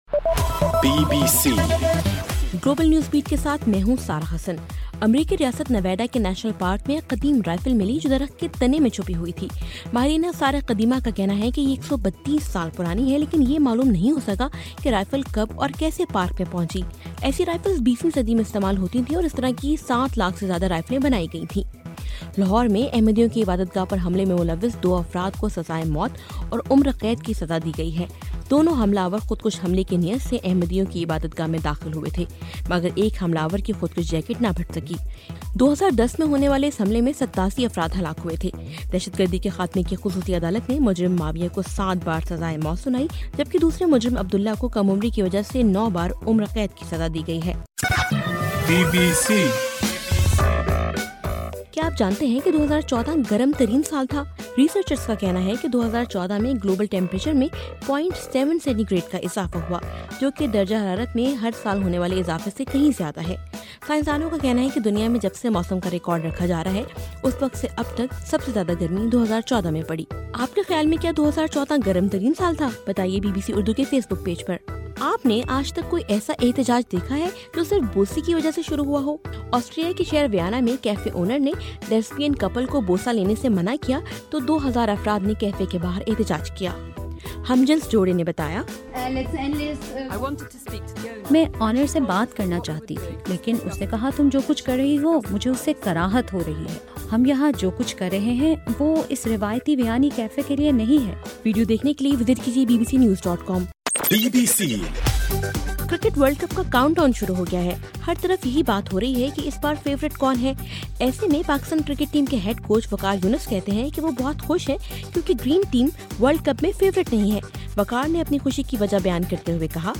جنوری 17: رات 8 بجے کا گلوبل نیوز بیٹ بُلیٹن